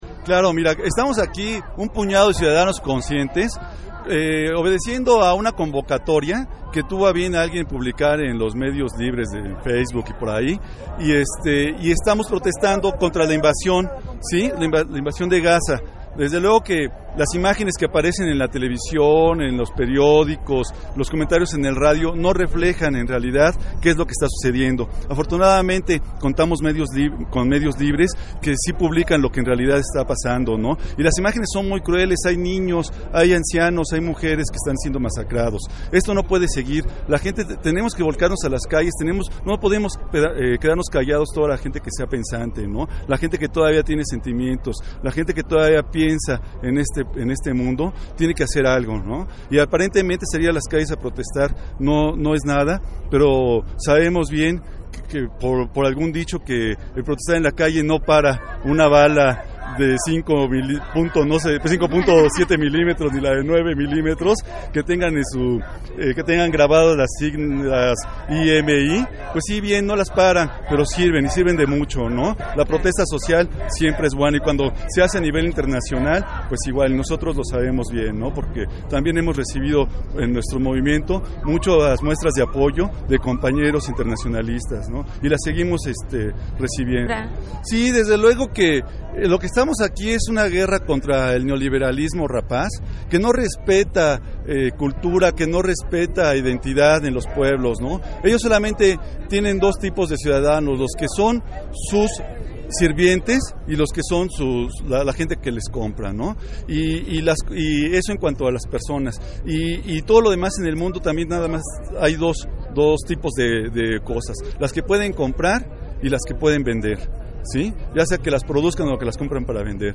Entrevista RedmixZapatista